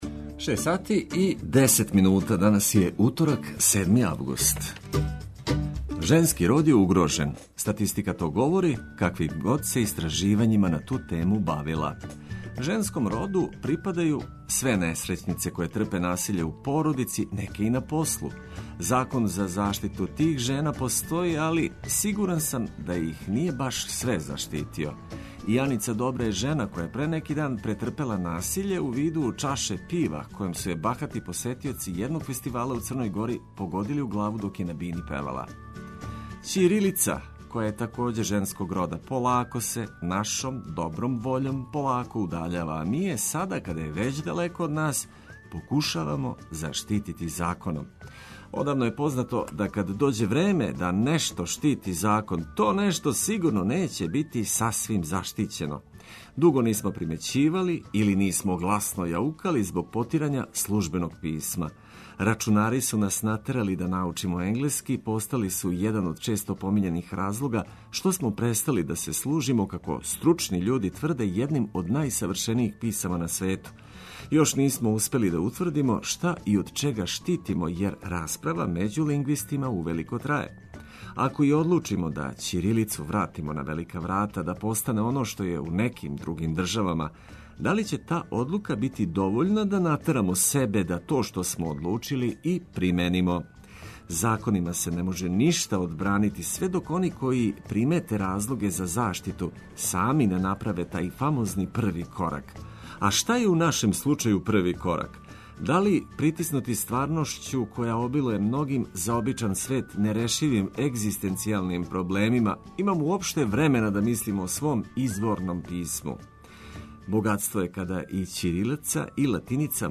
Током емисије само свеже информације и музика која освежава дух.